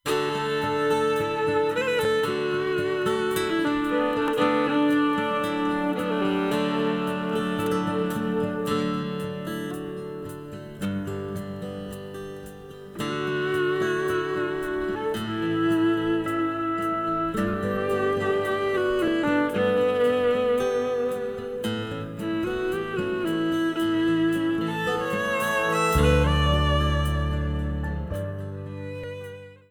This is an instrumental backing track cover.
• Key – E♭
• With Backing Vocals
• No Fade